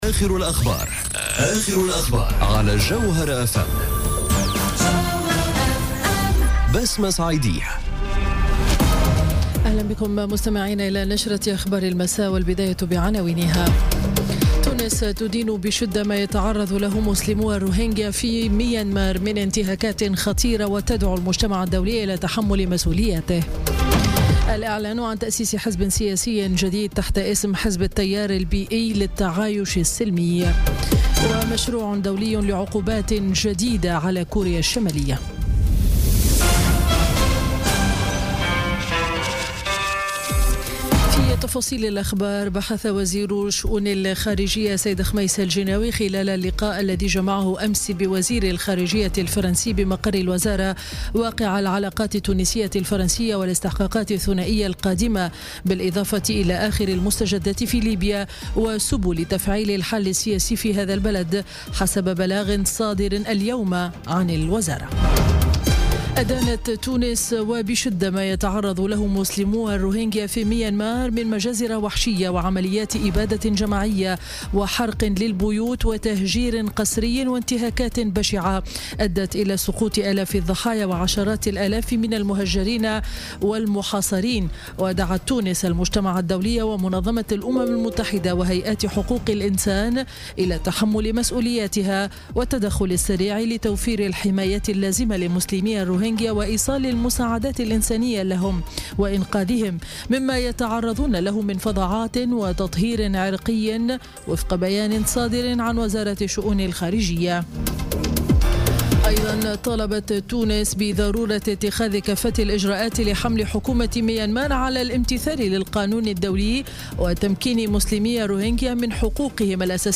نشرة أخبار السابعة مساء ليوم الاثنين 4 سبتمبر 2017